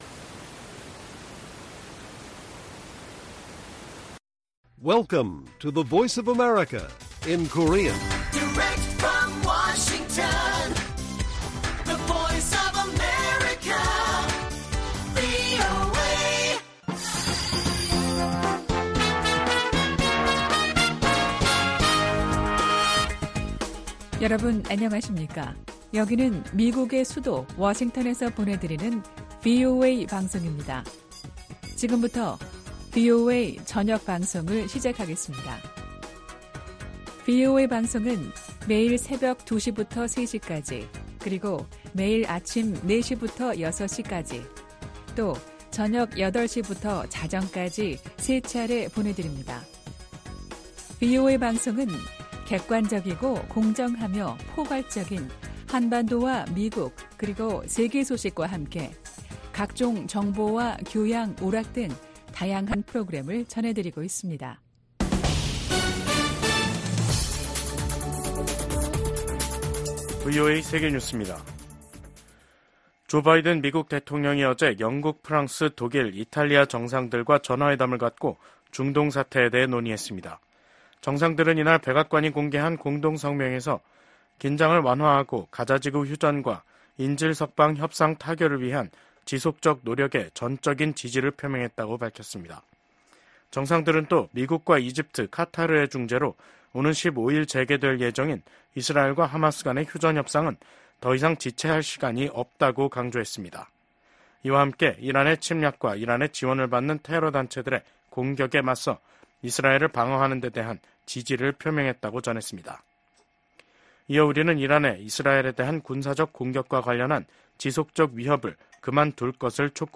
VOA 한국어 간판 뉴스 프로그램 '뉴스 투데이', 2024년 8월 13일 1부 방송입니다. 북러 군사 밀착이 우크라이나뿐 아니라 인도태평양 지역 안보에도 영향을 미칠 것이라고 미국 백악관이 지적했습니다.